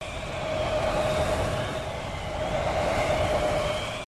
resources/phase_5/audio/sfx/tt_s_ara_cfg_whirlwind.ogg at daa254440b63a7e5fc4e5d9240dd576df58a84f6
tt_s_ara_cfg_whirlwind.ogg